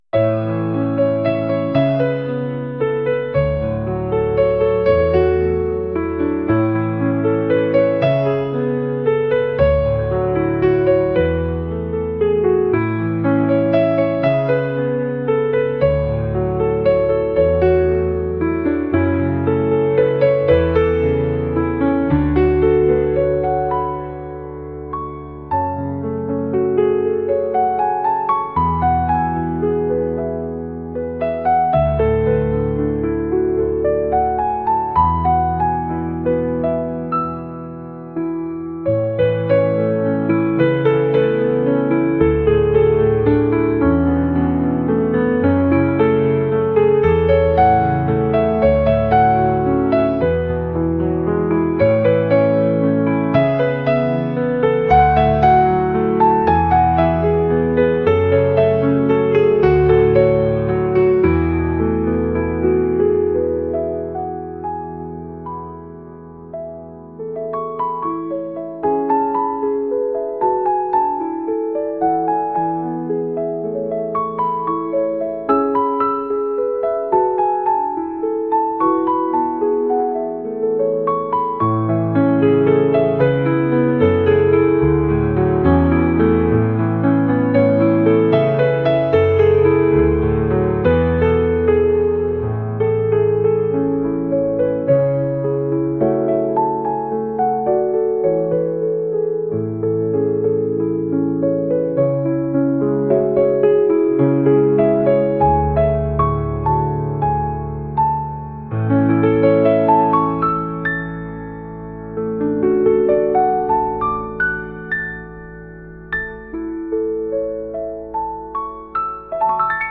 しっとりとした対話をするようななピアノ曲です。